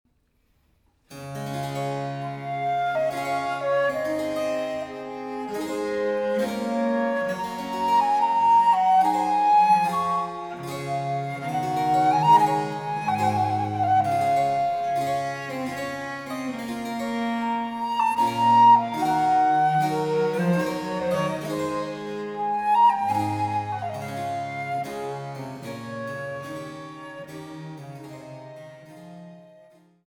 Vite